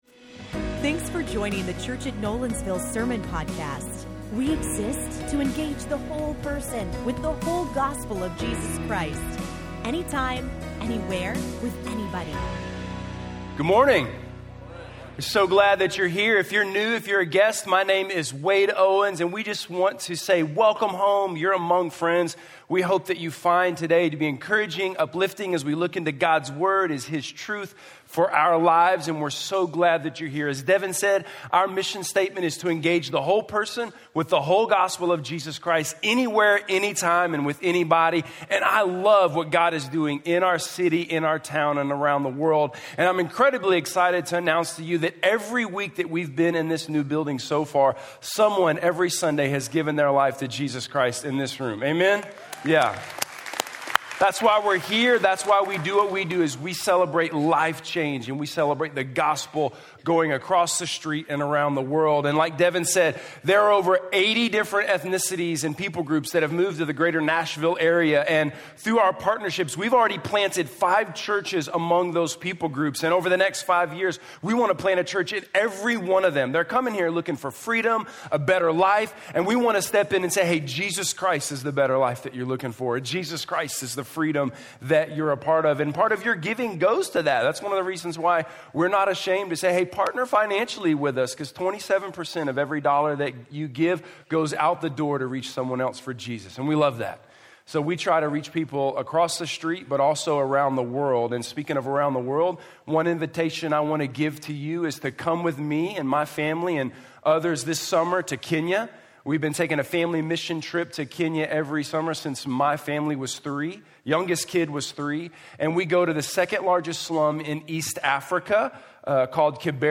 Dealing with Loneliness - Sermon - Nolensville